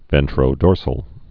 (vĕntrō-dôrsəl)